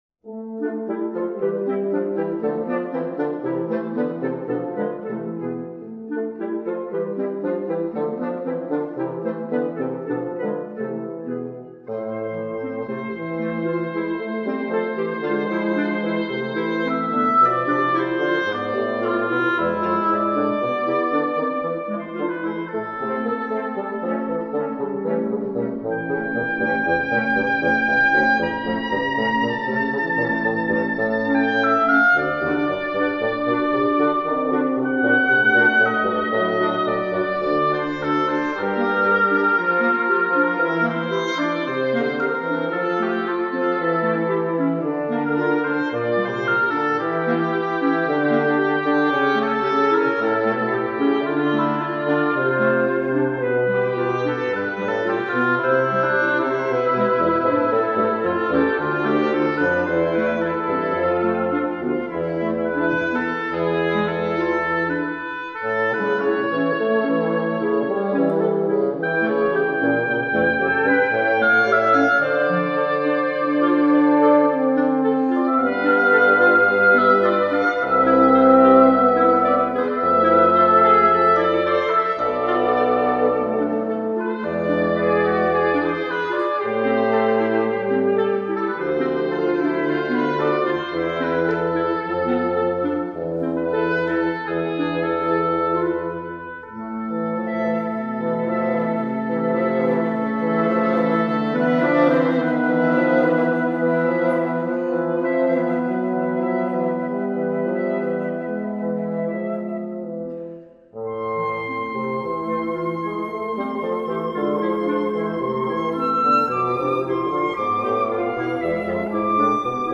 flauta
oboé
clarineta
trompa
fagote